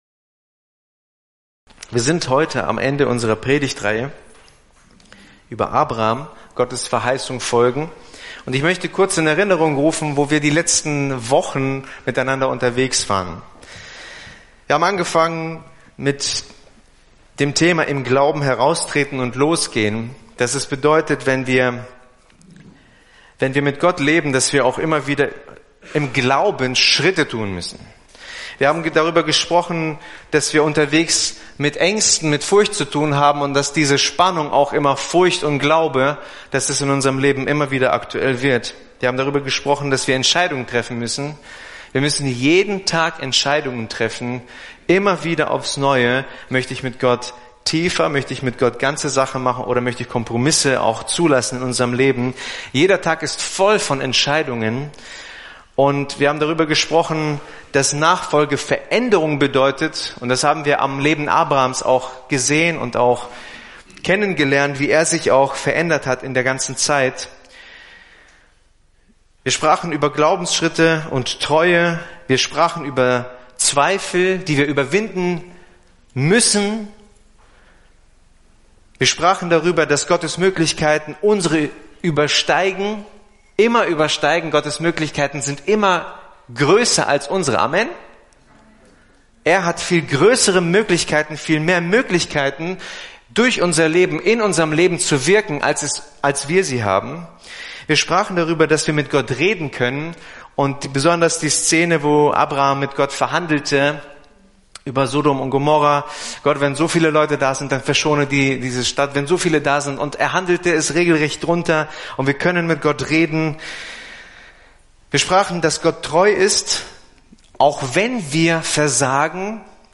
Download über den folgenden Link (rechte Maustaste -> Speichern unter…): Begleitmaterial zur Predigt